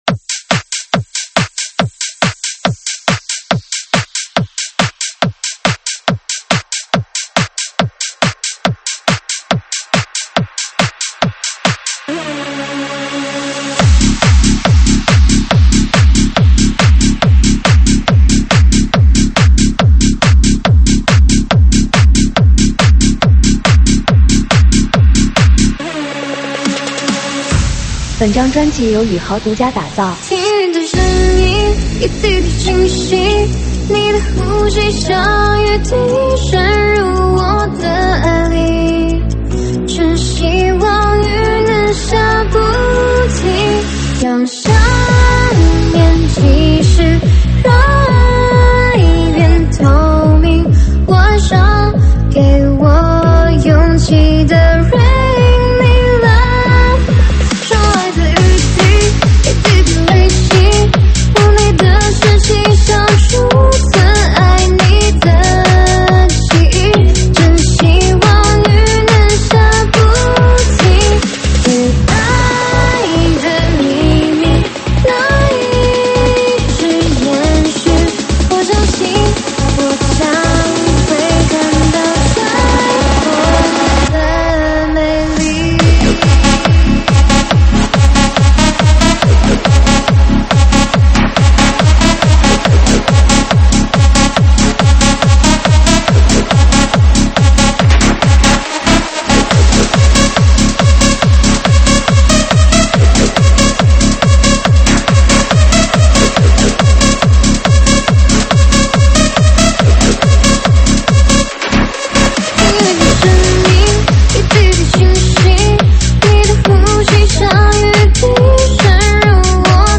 现场串烧
舞曲类别：现场串烧